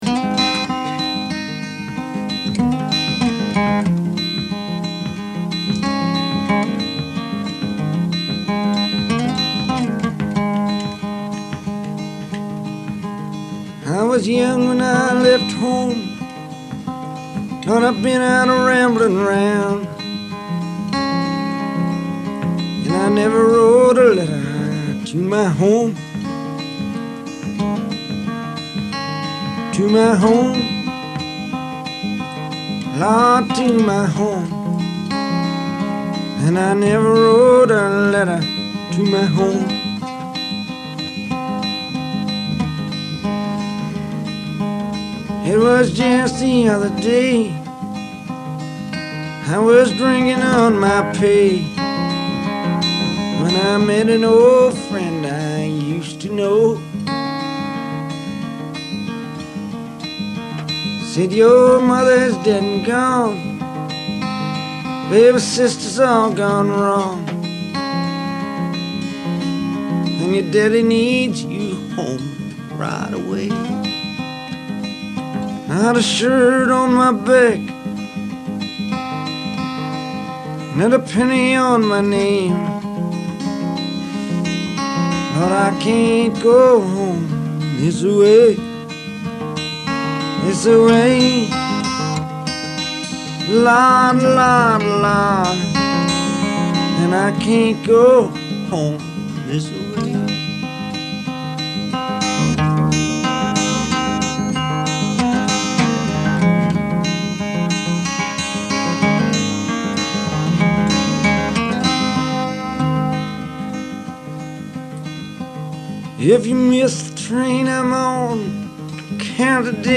’ were recorded in a friend’s Minneapolis apartment